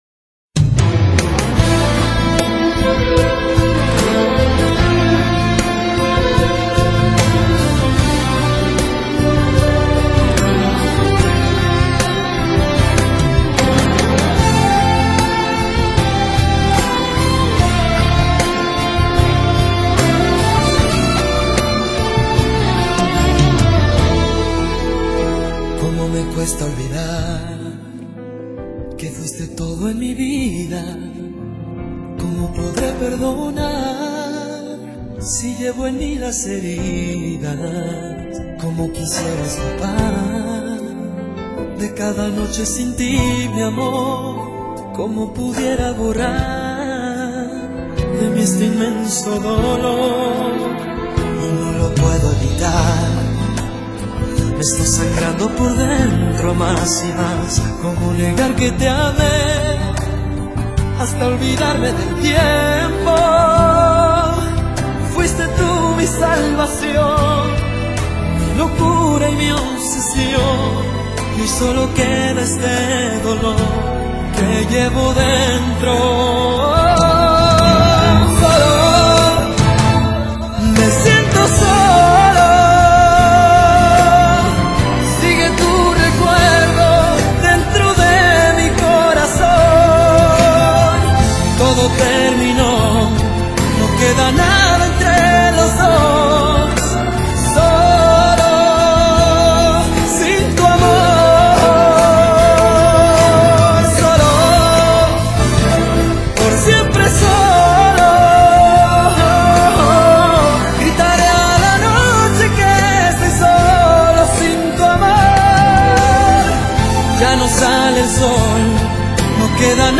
奔放动感，share一下! wma格式，音质一般，不过用电脑放着听就足够，enjoy it!